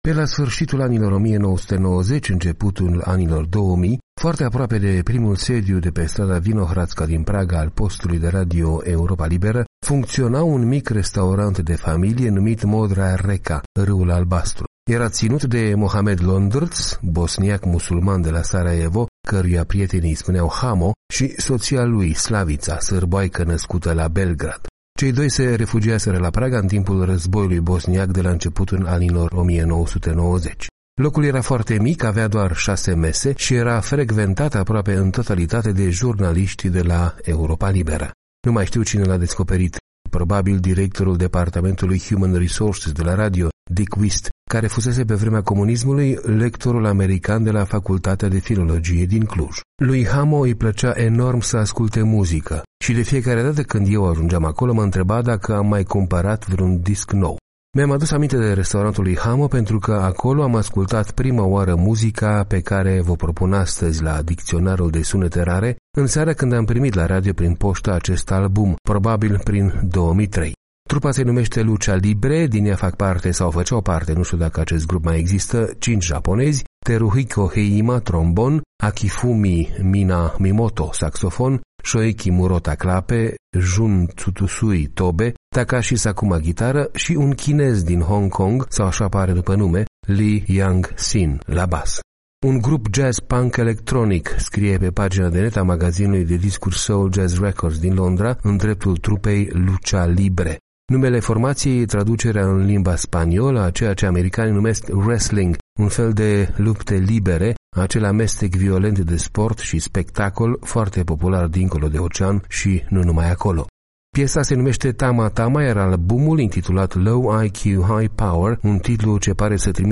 Un grup jazz/punk/electronic: cinci japonezi și un chinez de la Hong Kong.